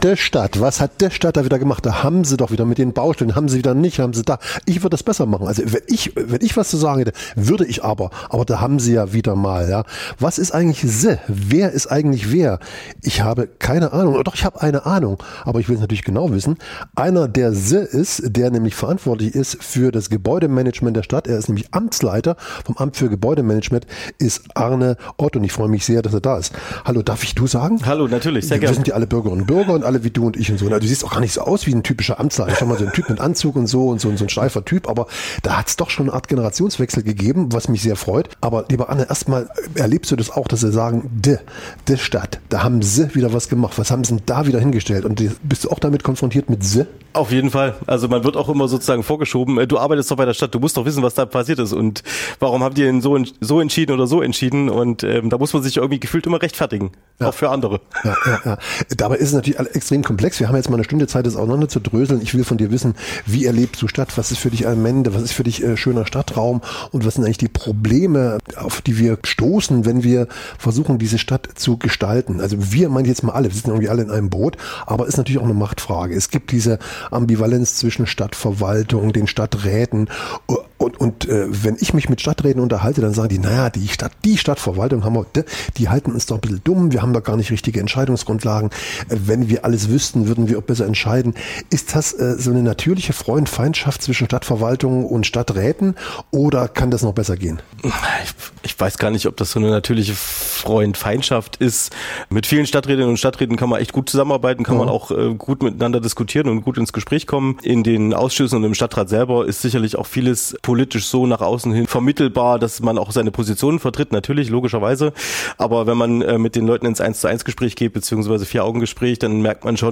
Wo Politik entscheidet, Verwaltung umsetzt und beides nicht immer gleichzeitig gelingt, entstehen Reibungen, die auch im Alltag spürbar werden. Ein Gespräch über Verantwortung, Grenzen � und die Suche nach besseren Lösungen für eine Stadt im Wandel.